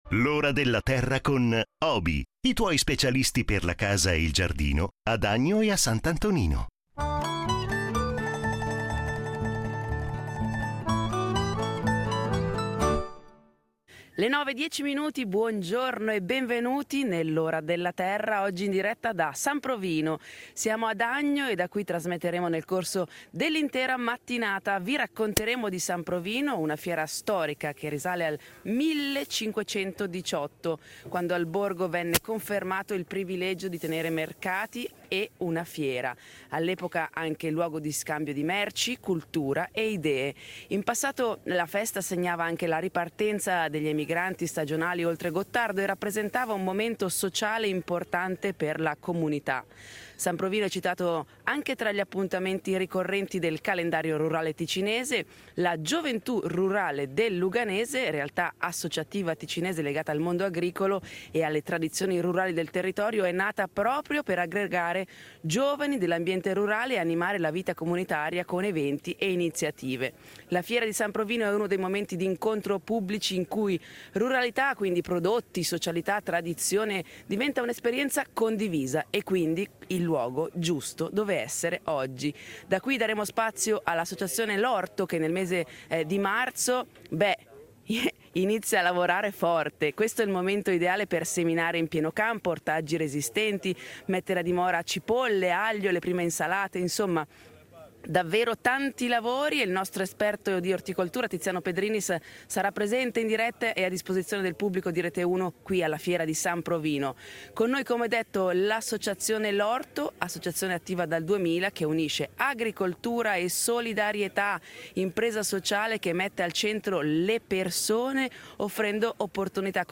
sarà presente in diretta e a disposizione del pubblico di Rete Uno alla fiera di San Provino.